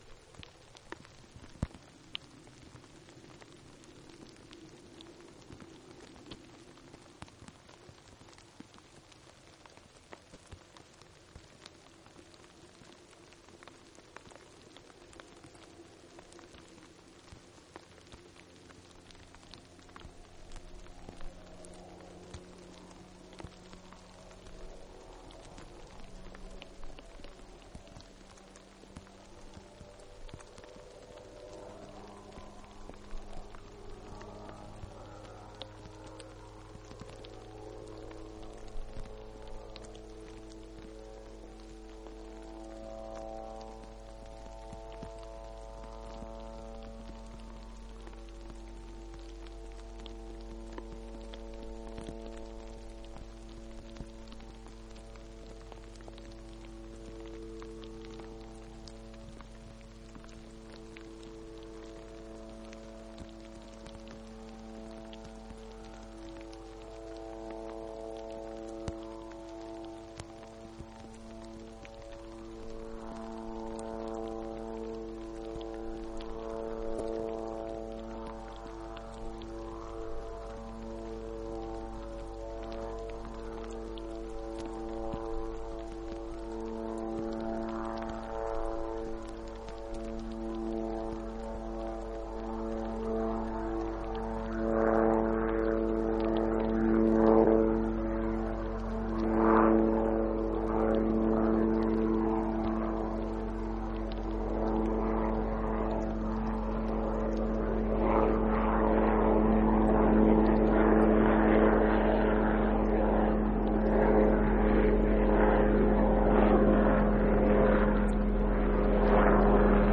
Through a gentle rain an MD-500 helicopter approaches the microphone. At first only the tail rotor is audible, then eventually the main rotor becomes audible as well. McKinley River, Denali National Park.